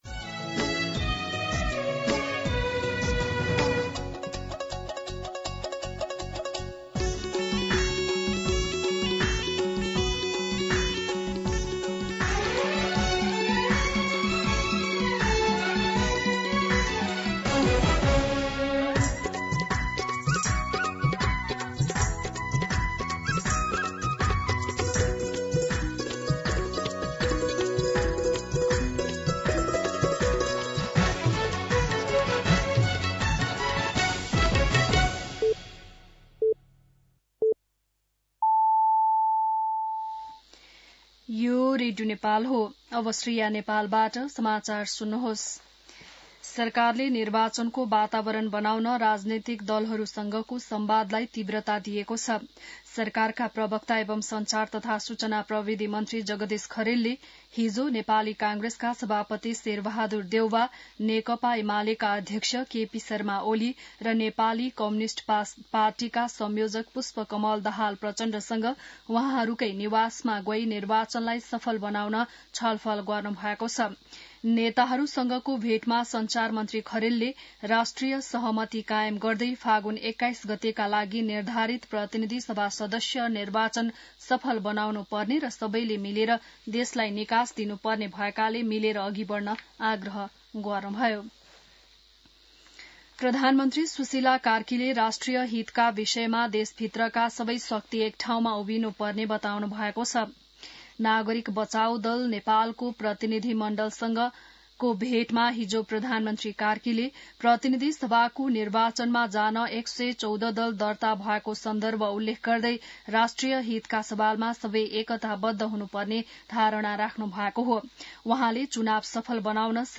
बिहान ११ बजेको नेपाली समाचार : १८ पुष , २०२६
11-am-Nepali-News-5.mp3